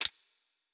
Fantasy Interface Sounds
UI Tight 11.wav